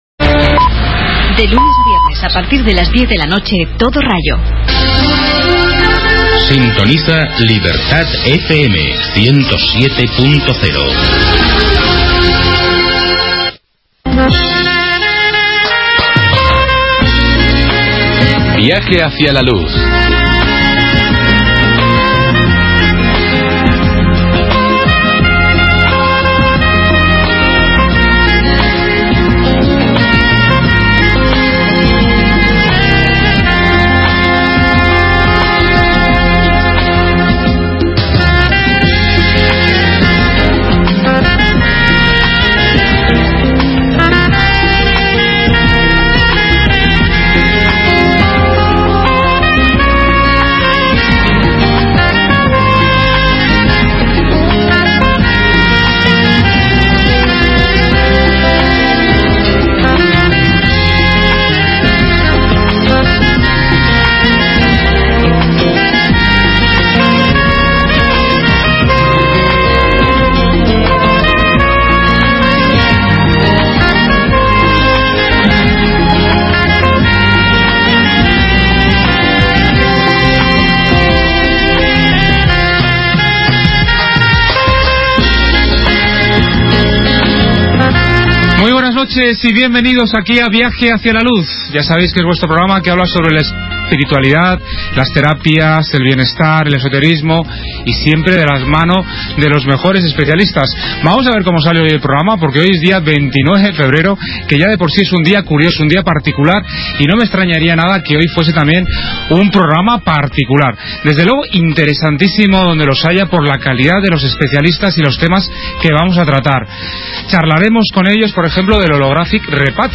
Septiembre de 2006 Entrevista en Radio Libertad Enero de 2009.